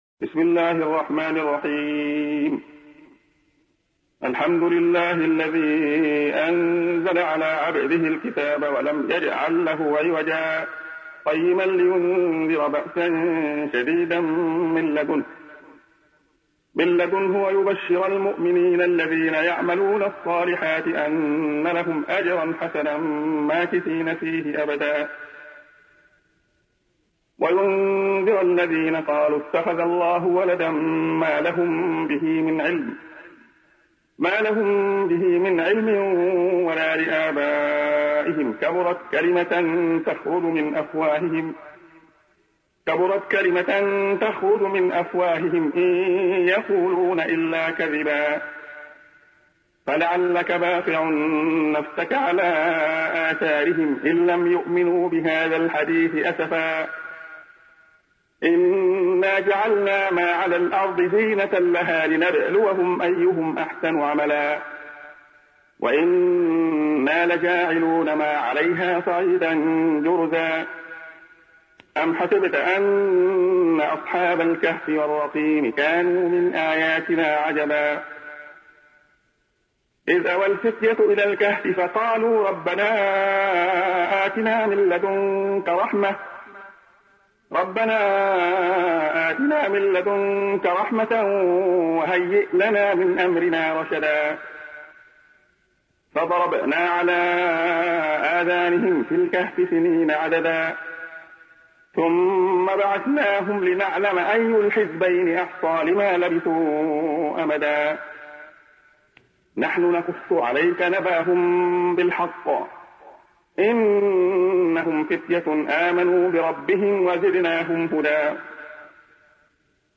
تحميل : 18. سورة الكهف / القارئ عبد الله خياط / القرآن الكريم / موقع يا حسين